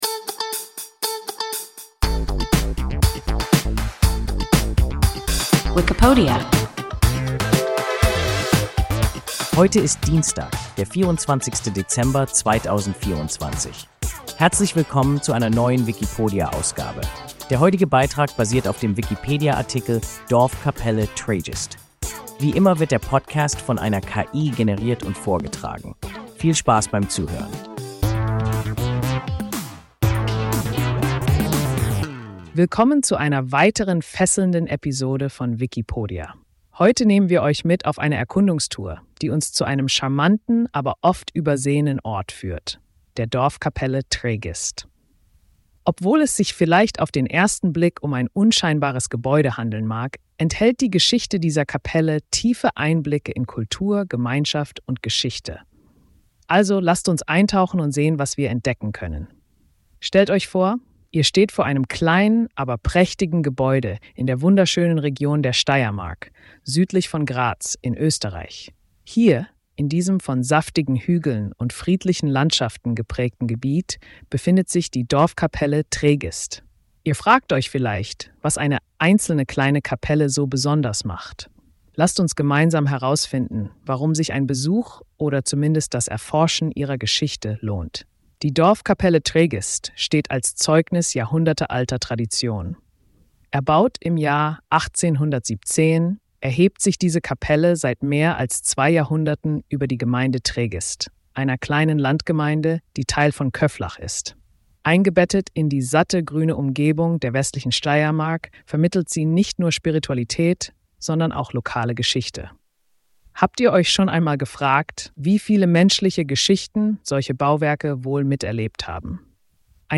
Dorfkapelle Tregist – WIKIPODIA – ein KI Podcast